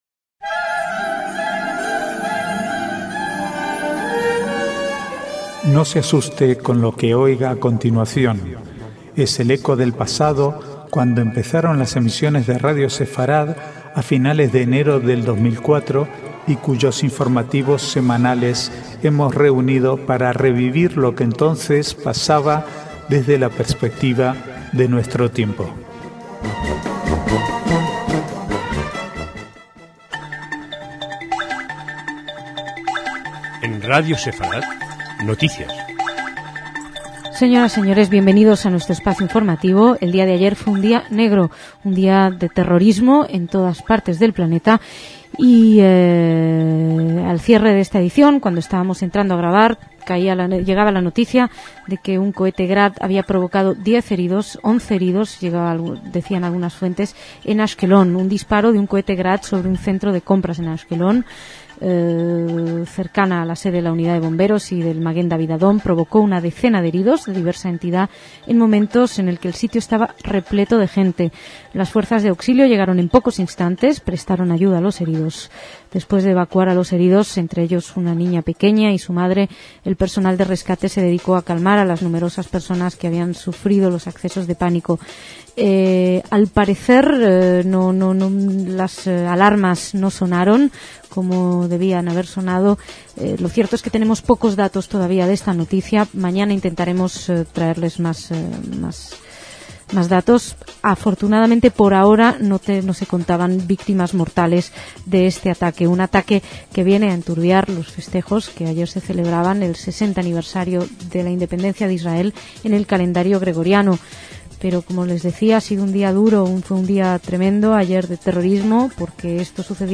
Archivo de noticias del 15 al 20/5/2008